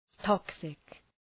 {‘tɒksık}